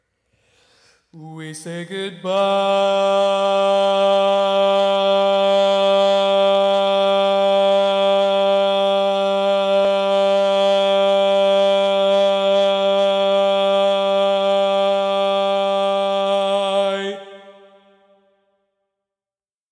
Type: Barbershop
Each recording below is single part only.
Learning tracks sung by